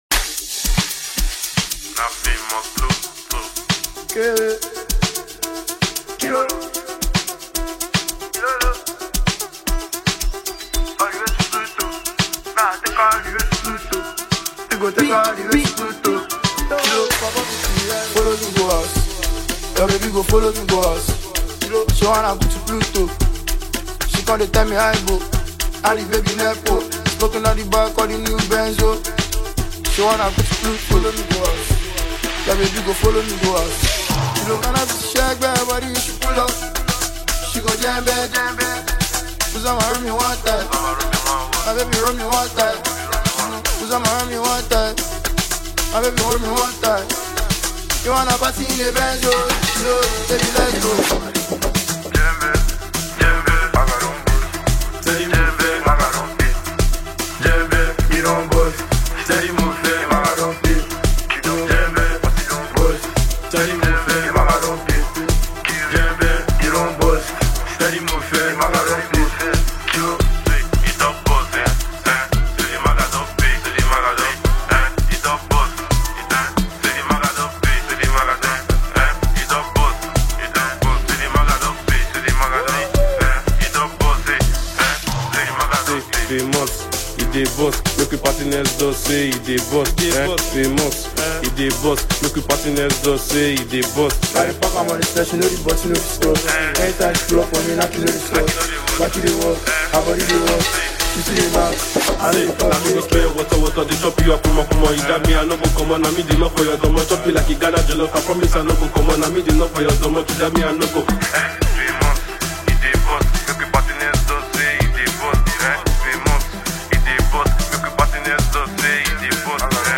catchy new single
With its infectious groove